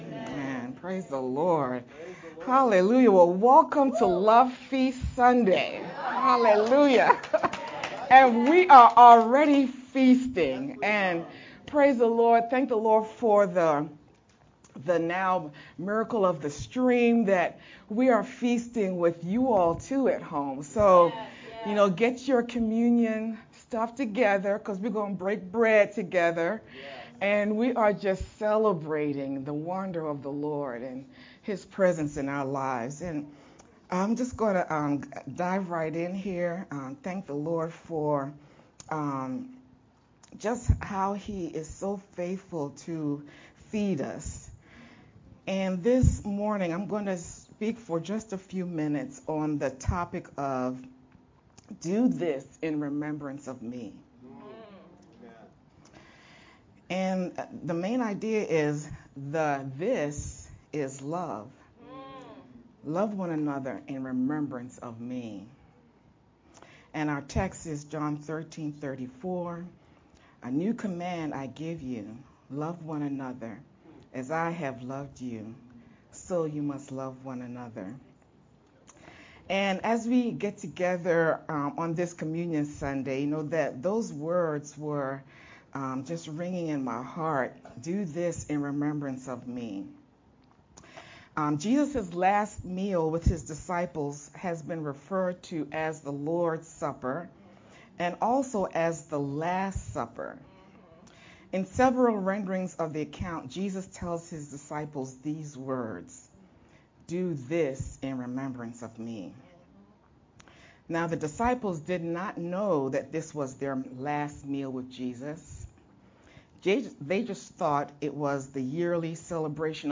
Aug-28th-Sermon-only_Converted-CD.mp3